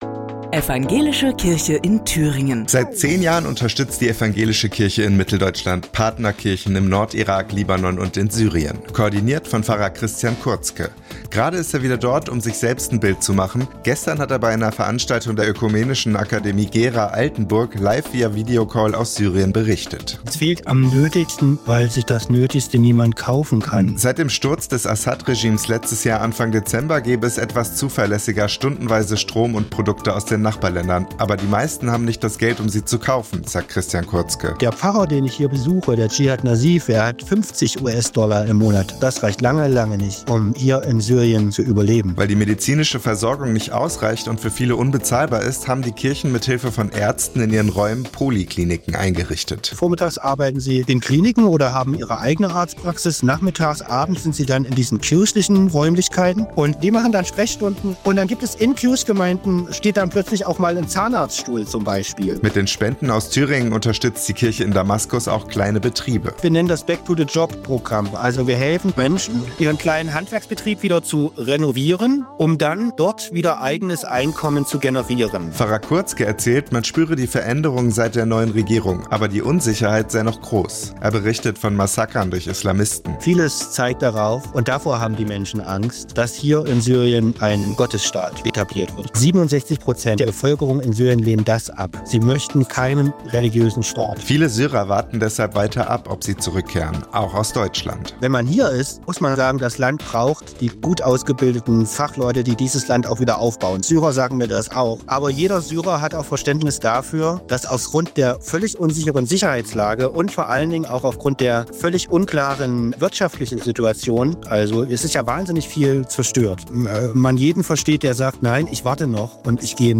Gestern hat er bei einer Veranstaltung der Ökumenischen Akademie Gera/Altenburg live via Videocall aus Syrien berichtet.